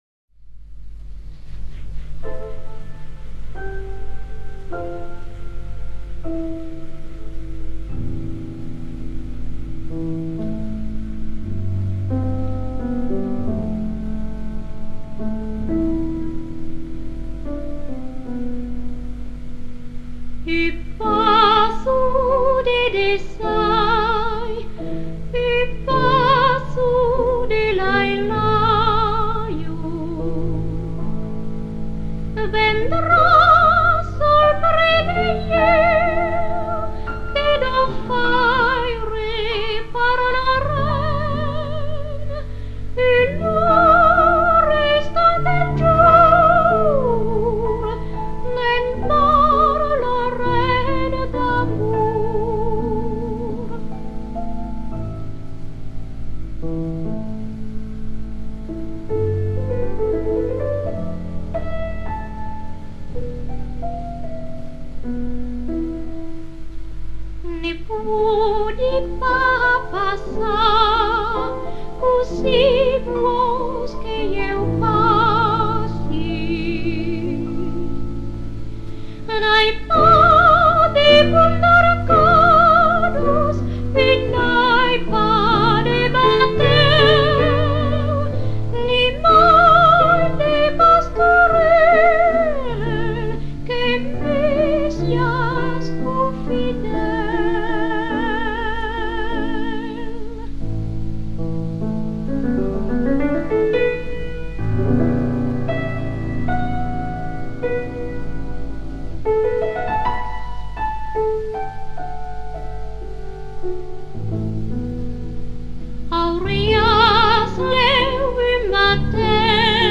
这里提供的是一套单声道老唱片，文件来自IMG网站。